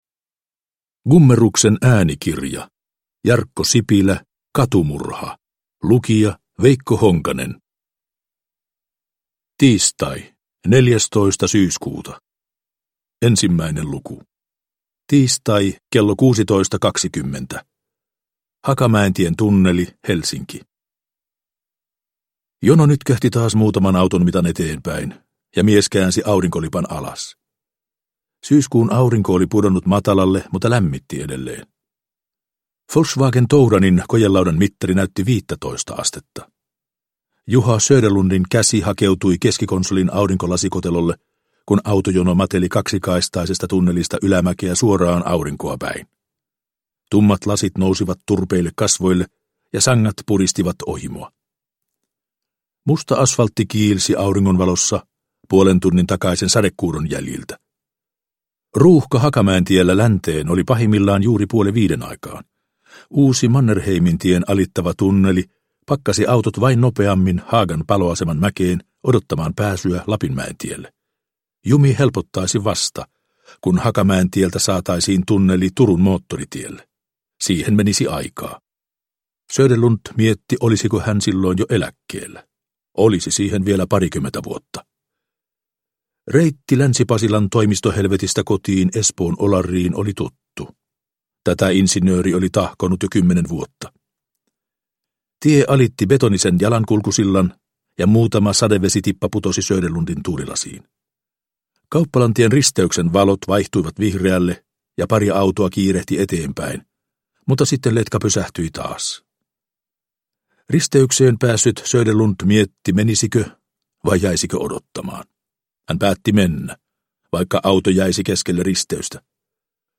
Katumurha – Ljudbok – Laddas ner